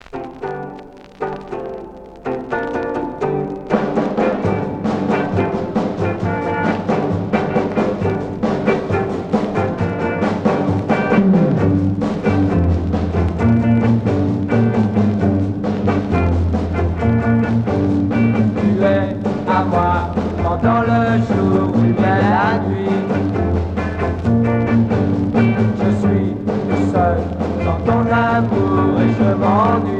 Beat rock